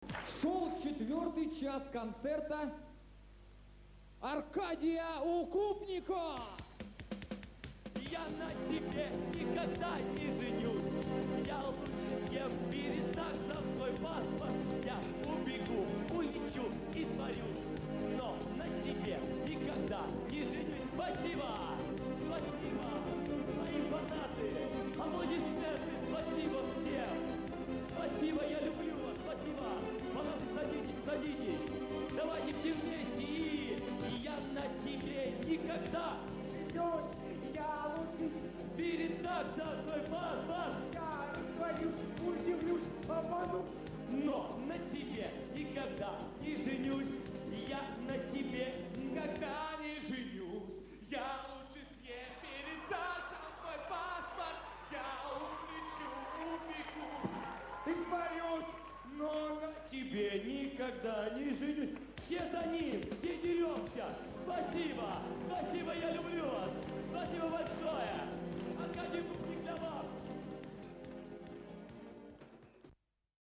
Финал 2005 Лиги КВН-Сибирь